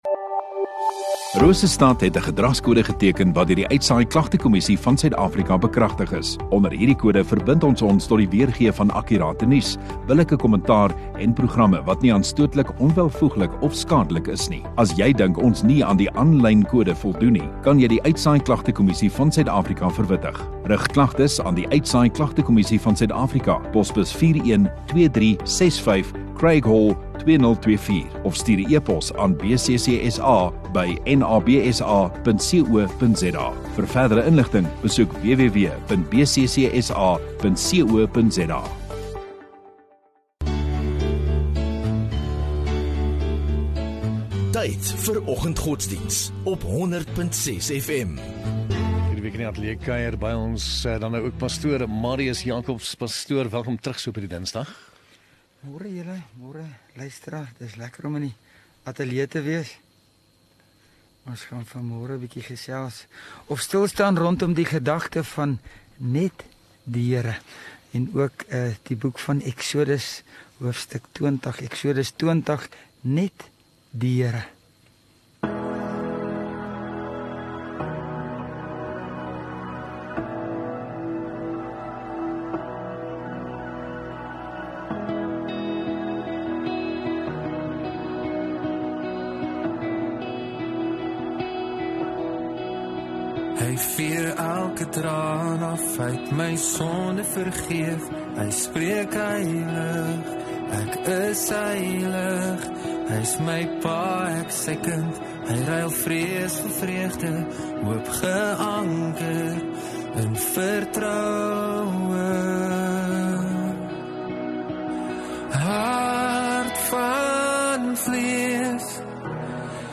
14 May Dinsdag Oggenddiens